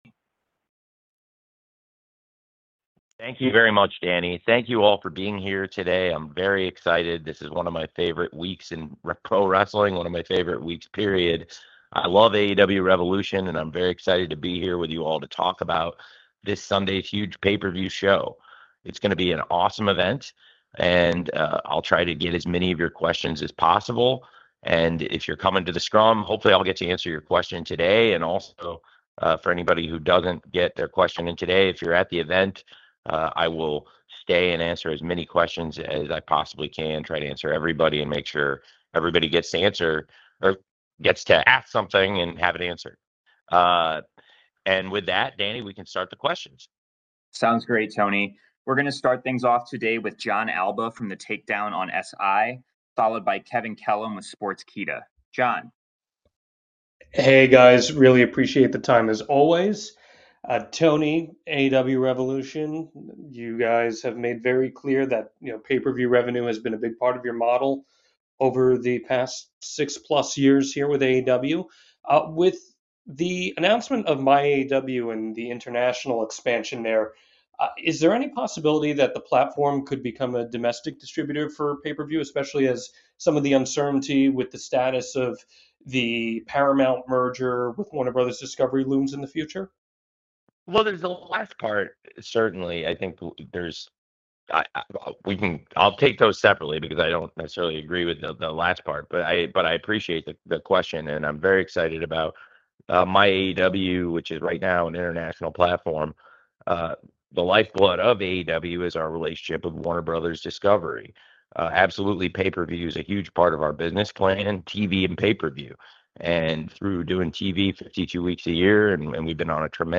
Tony Khan AEW media call notes: Samoa Joe update
Tony Khan spoke to the media for an hour Thursday about AEW Revolution, Samoa Joe, Paramount, and MyAEW.